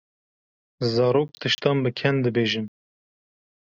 Pronounced as (IPA)
/kɛn/